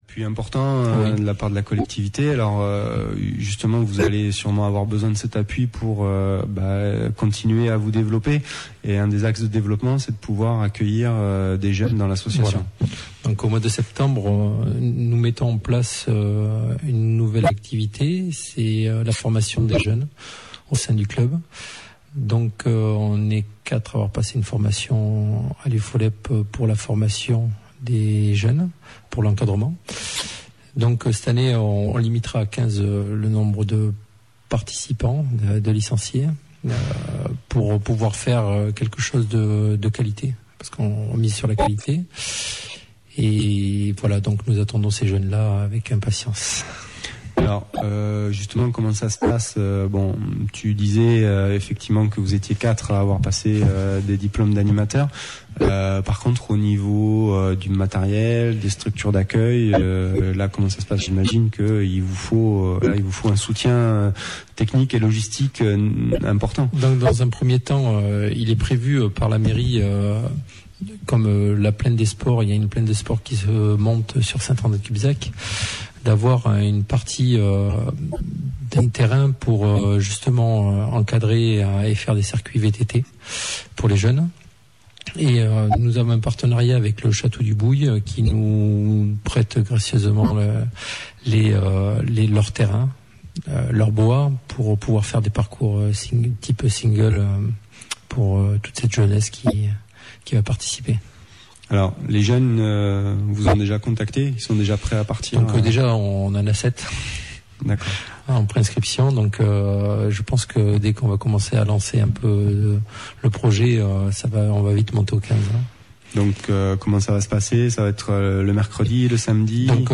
Reportage sur RIG
Notre cher Président a été interviewé sur la radio local RIG.
Malheureusement vous n’aurez qu’une partie de cet entretien pour des raisons techniques.